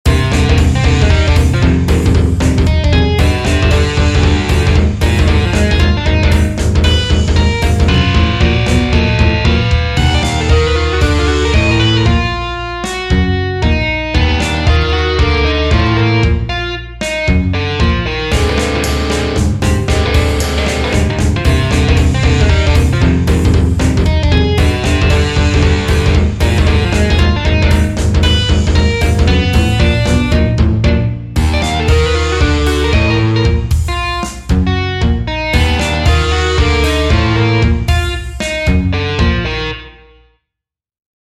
Metal.